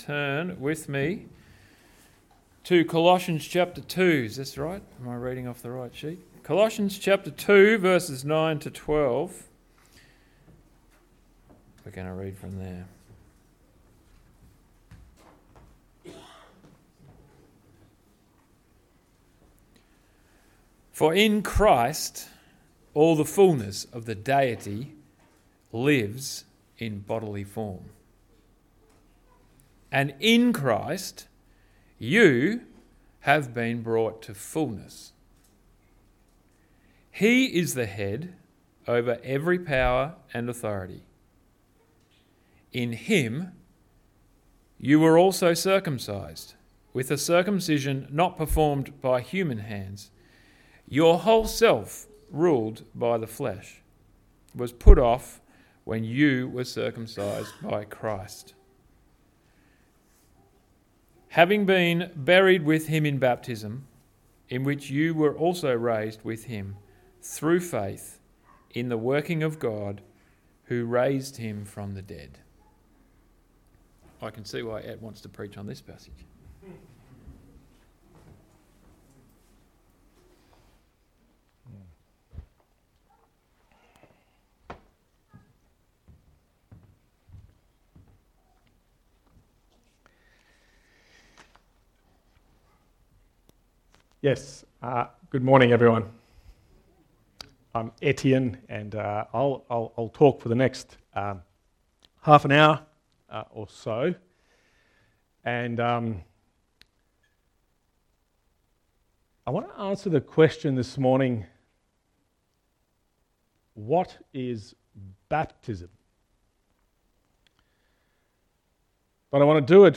Text: Colossians 2: 9-12 Sermon